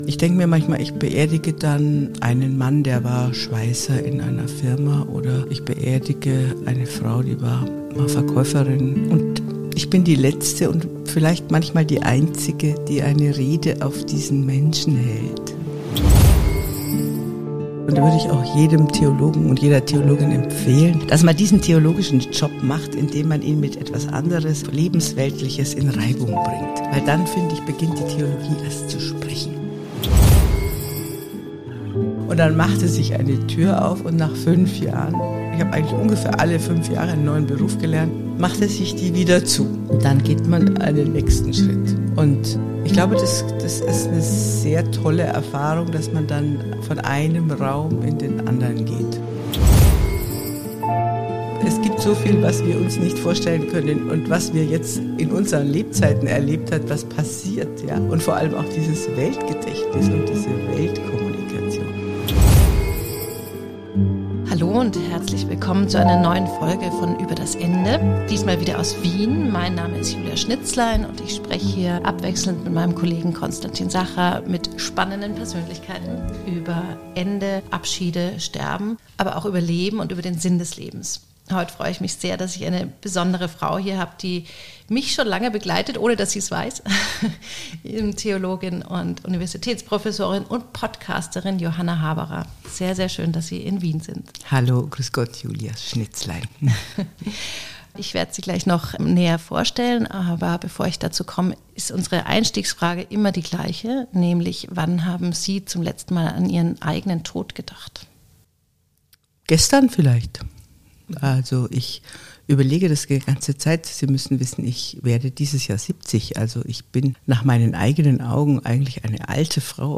Ein Gespräch über Würde, Sprachkraft und die Freiheit des Alters.